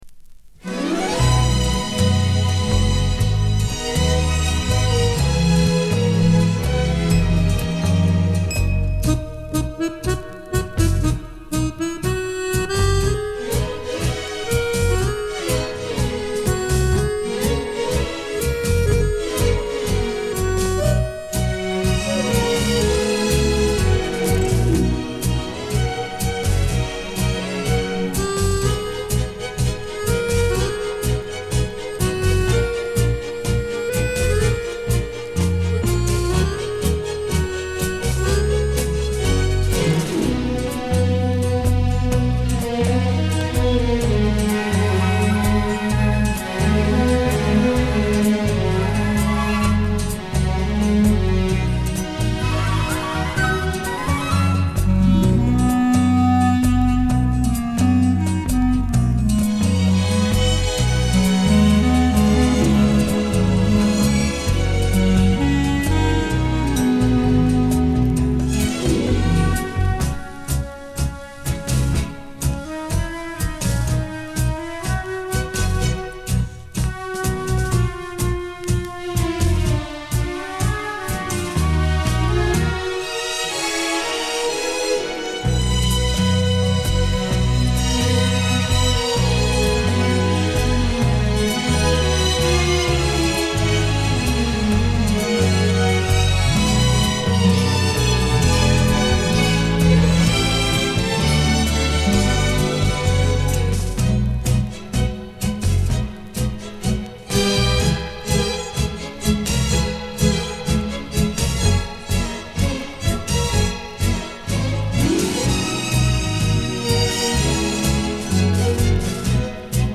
Genre:Latin
Style:Tango, Bolero